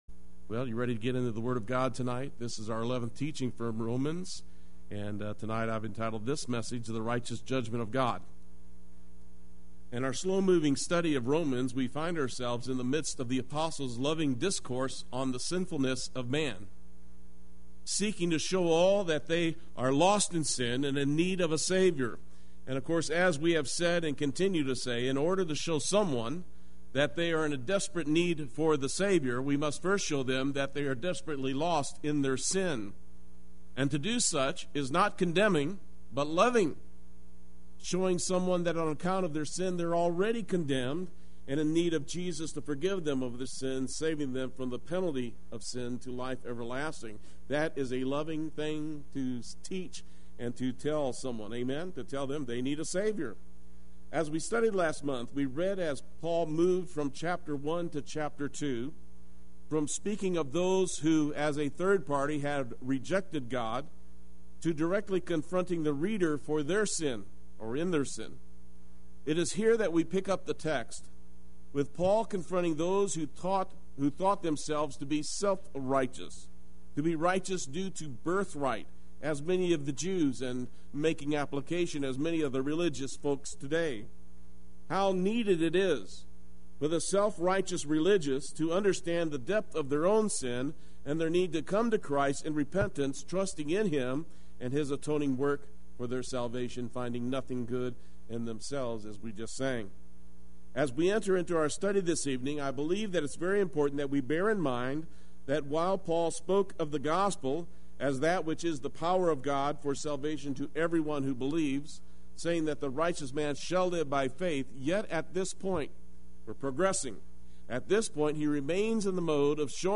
Play Sermon Get HCF Teaching Automatically.
The Righteous Judgement of God Wednesday Worship